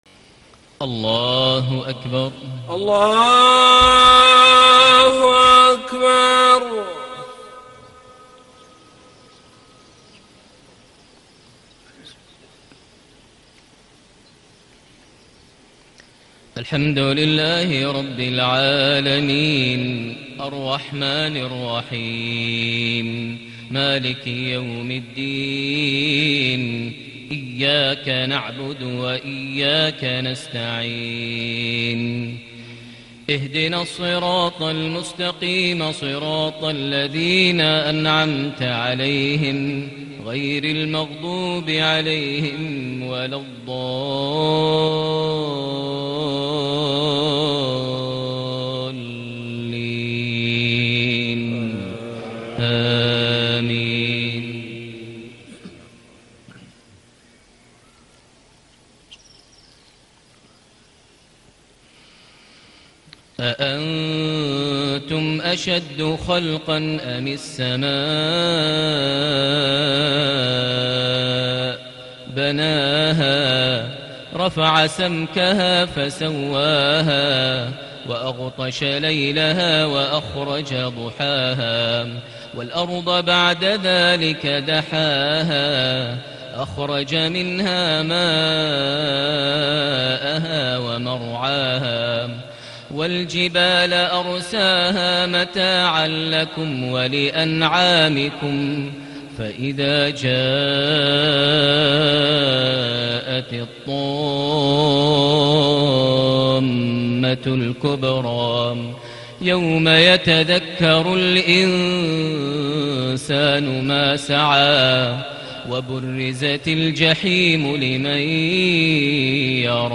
صلاة المغرب ٣ ربيع الأول ١٤٣٨هـ خواتيم النازعات / والفجر > 1438 هـ > الفروض - تلاوات ماهر المعيقلي